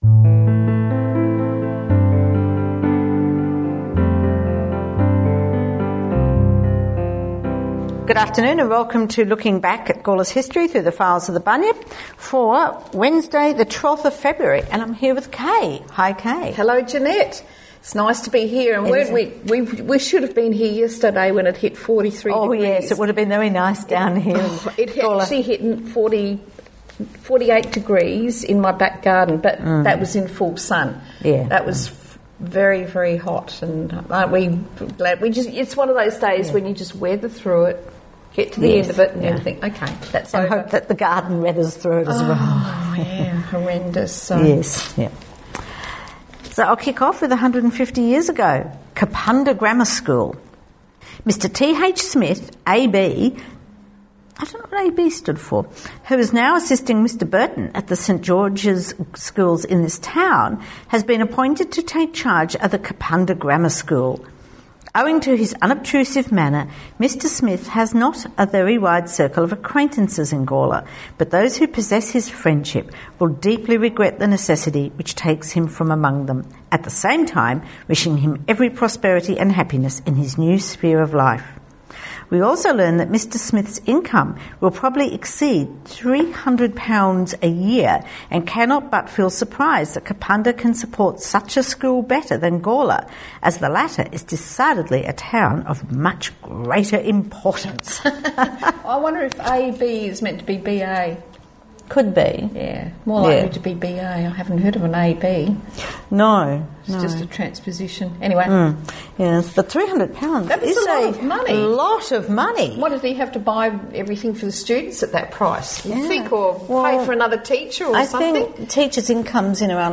Opening and closing music